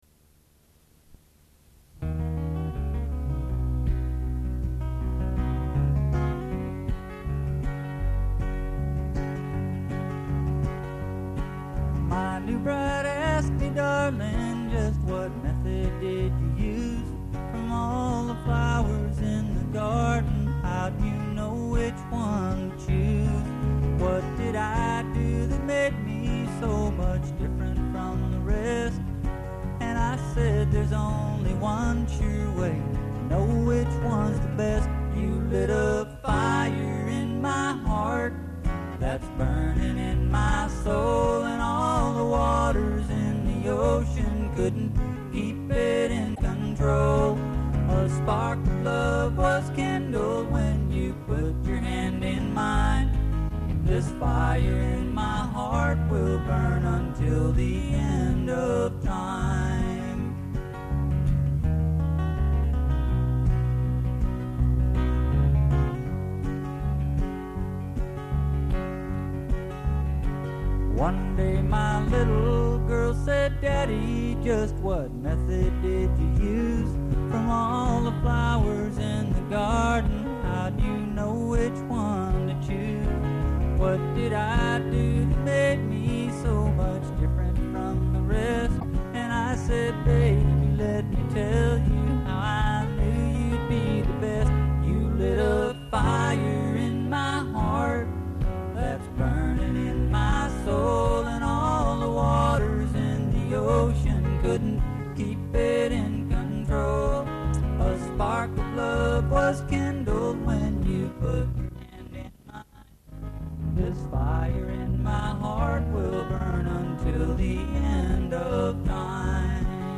8-TRACK DEMO(poor quality tape)